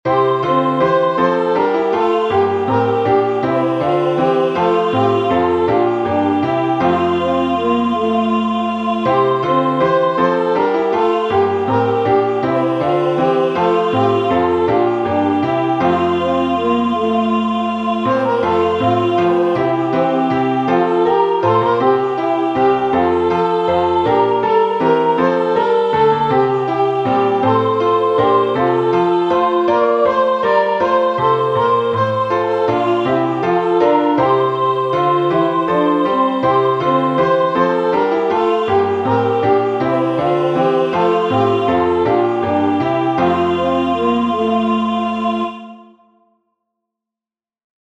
Acclamation (UK 24)Prepare the Way